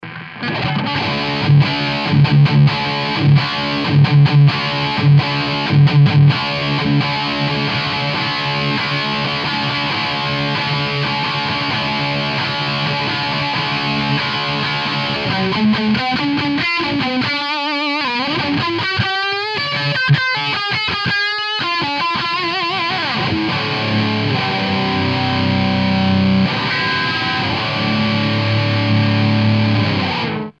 Druha ukazka je nachlp to iste, to iste nastavenie, nepohnuty mikrofon, ale preamp ide do koncaku s 2xEL34.
Oba koncaky boli vytocene do rovnakej hlasitosti, pomerne vysokej. EL34 uz isli trochu do skreslenia.
Skoda, ze je rozdielny koncak, lebo tie 6L6 maju vacsie gule a EL34 zasa viac takeho stredoveho dz-dz, ale kto vie co z toho je sposobene lampami a co koncakom ako takym.
EL34.mp3